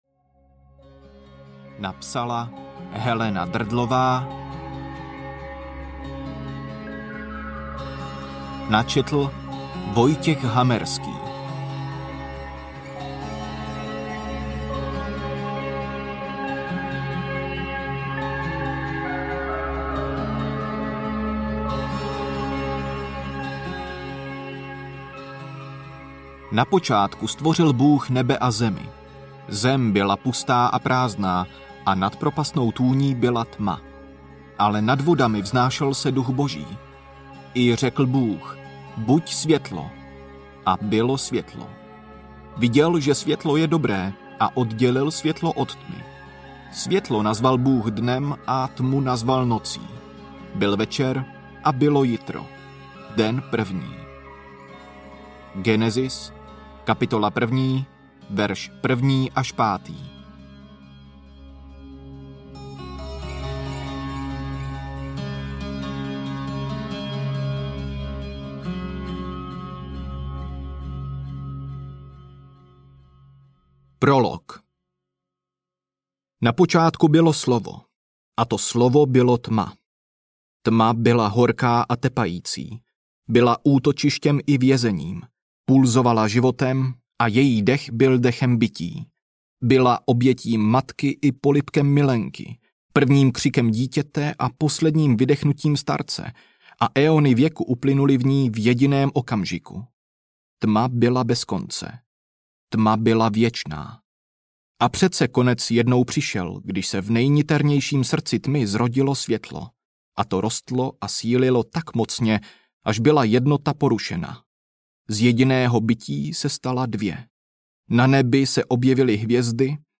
Uteč, než doroste měsíc audiokniha
Ukázka z knihy